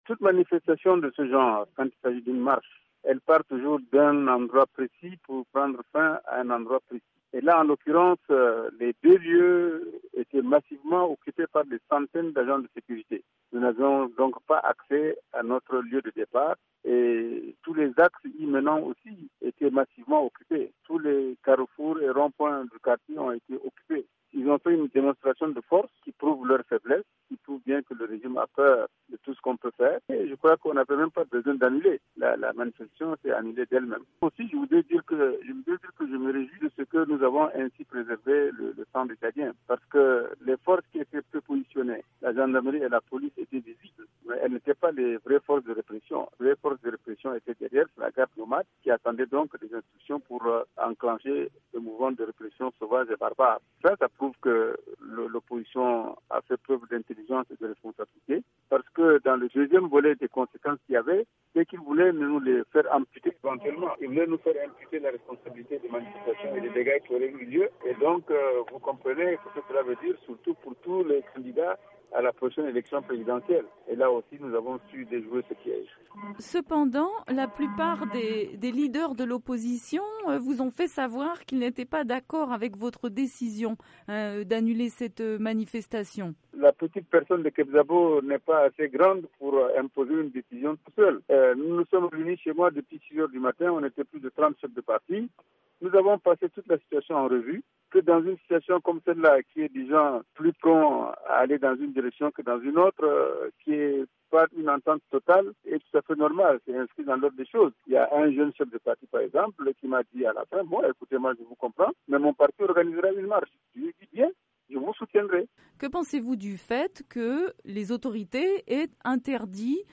Dans une interview à VOA Afrique, le chef de l’opposition tchadienne explique que la marche prévue mardi s’est annulé d’elle-même étant donné le bouclage policier de tous les axes à NDjaména.